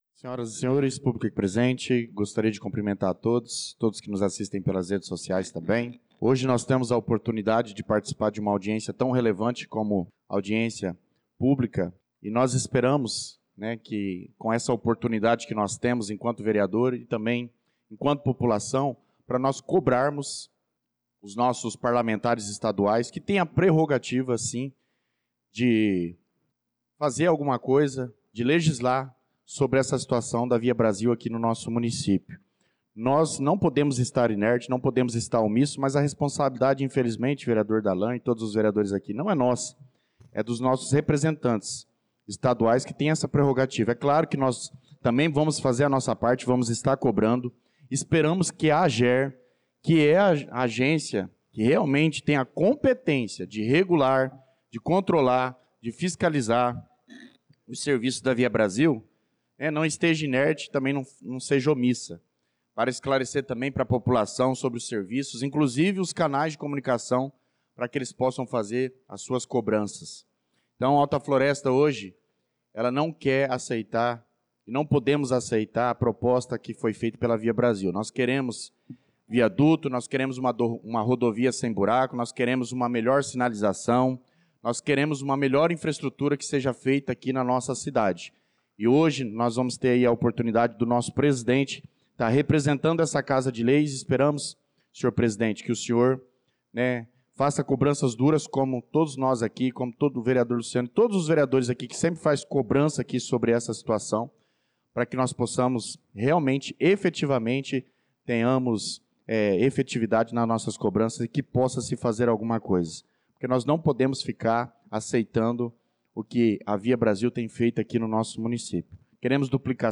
Pronunciamento do vereador Douglas Teixeira na Sessão Ordinária do dia 18/03/2025